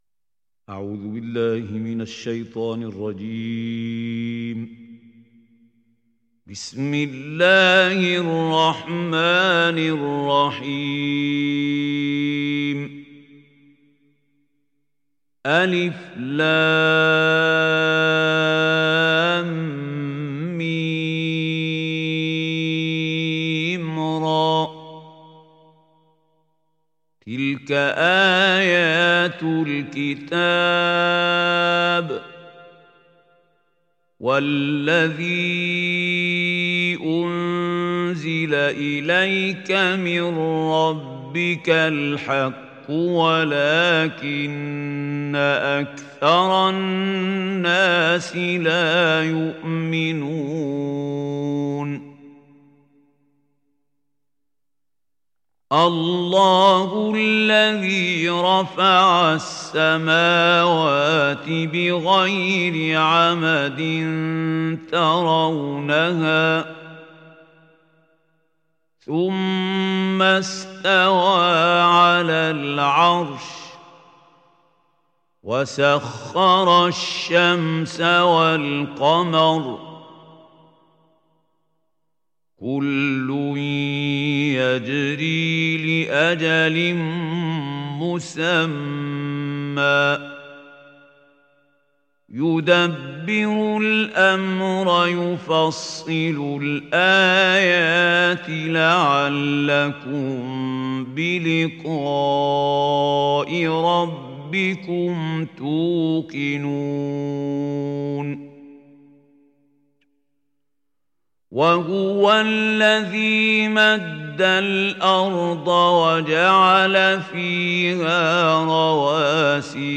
Surat Ar Rad mp3 Download Mahmoud Khalil Al Hussary (Riwayat Hafs)
Surat Ar Rad Download mp3 Mahmoud Khalil Al Hussary Riwayat Hafs dari Asim, Download Quran dan mendengarkan mp3 tautan langsung penuh